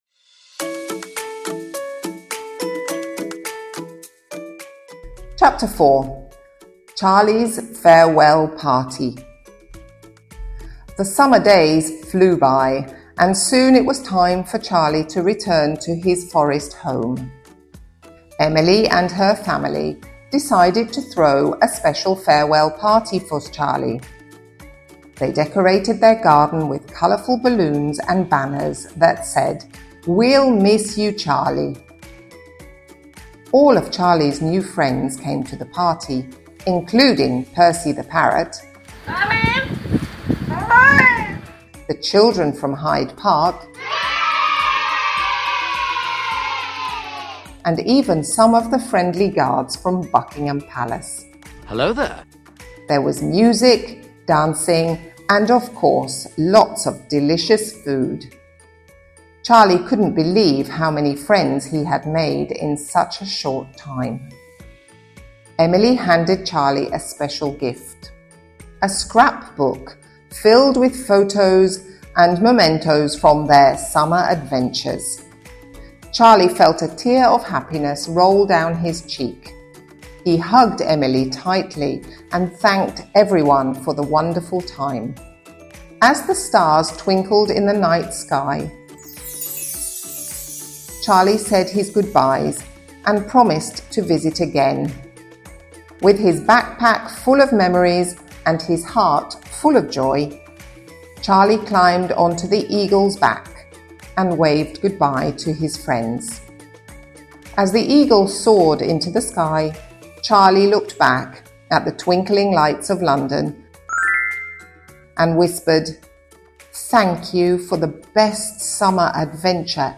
sound effects from Pixabay